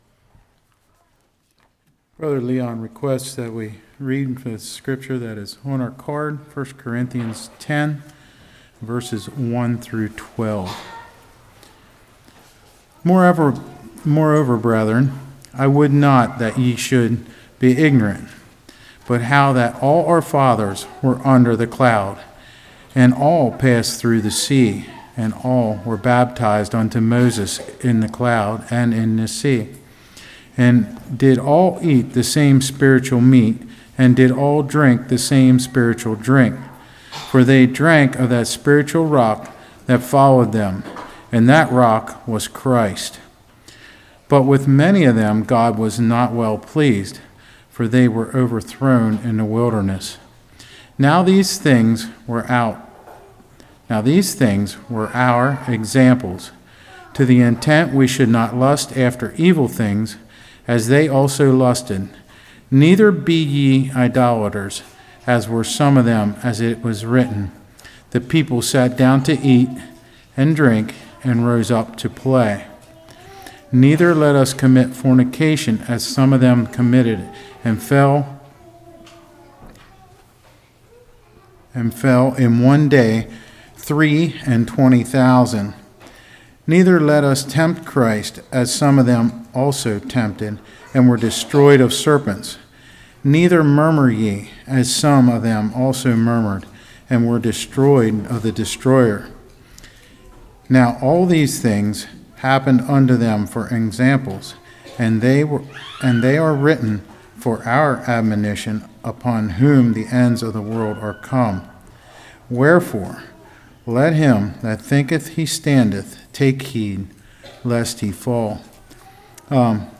1 Corinthians 10:1-12 Service Type: Revival Rock Strengthens Us Rock Hides Us Rock of Judgment « Water